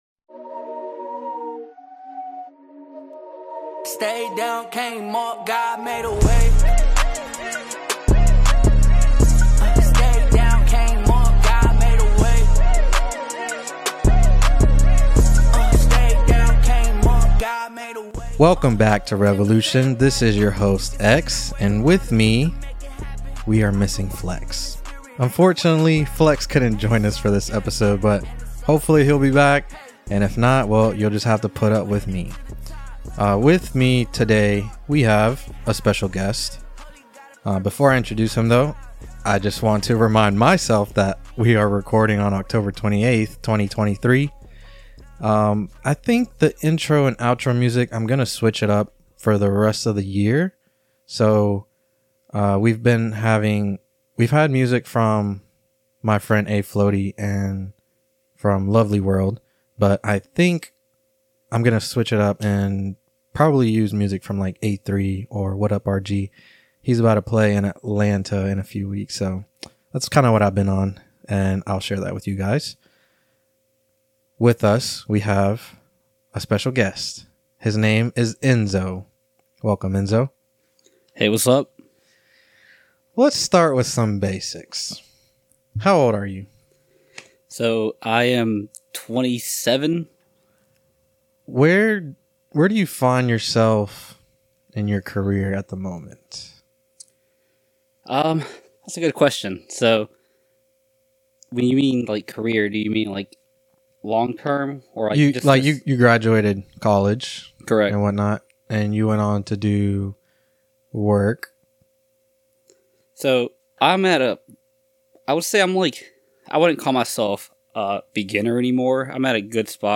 A well rounded interview of a long-time friend of mine. We cover things like work, fitness, marriage, religion, and many others.